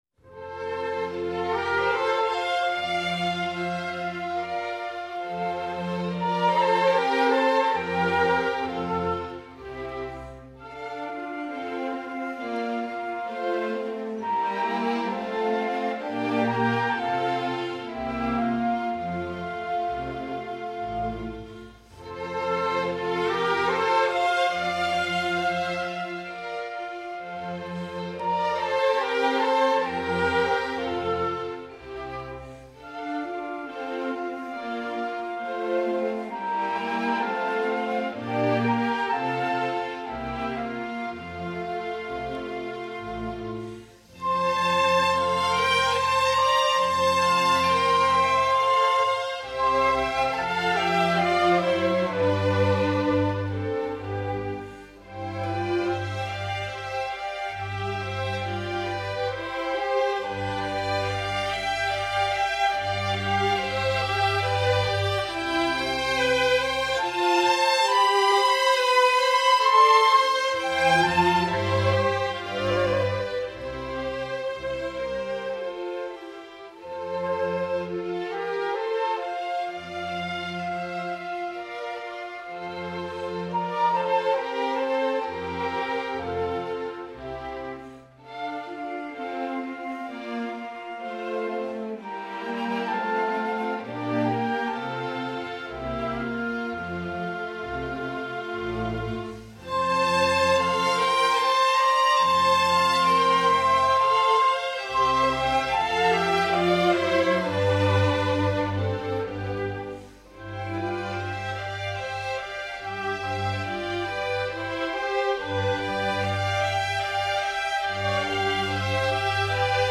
Orfeo ed Euridice) — опера К. Ф. Глюка, созданная в 1762 году на сюжет греческого мифа об Орфее.
Сцена в аду заканчивалась музыкой финала из балета «Дон Жуан»; в музыку «блаженных теней» введено знаменитое соло флейты, известное в концертной практике как «Мелодия» Глюка.
orfeo_ed_euridice_dance_of_the_ble-7879.mp3